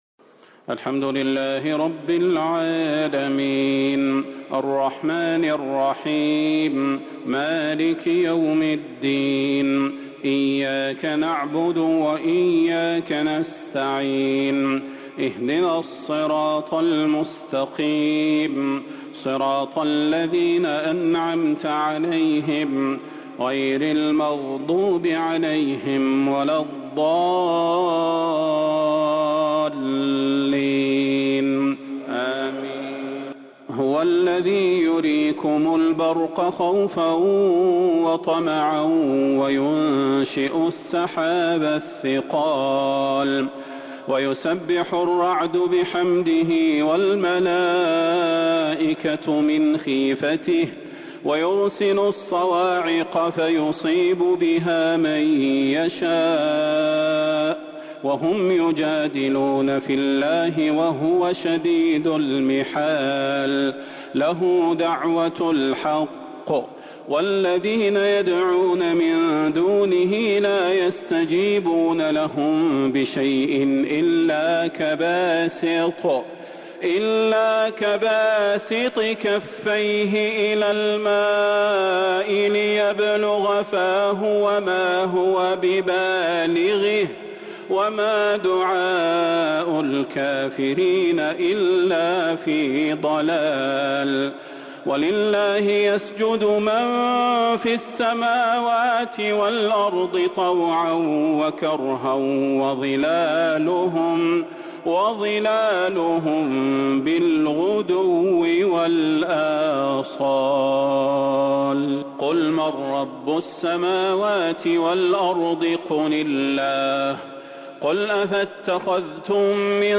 تراويح ليلة 17 رمضان 1444هـ من سورتي الرعد (12-43) و إبراهيم (1-18) | taraweeh 17st night Ramadan 1444H Surah Ar-Ra'd and Ibrahim > تراويح الحرم النبوي عام 1444 🕌 > التراويح - تلاوات الحرمين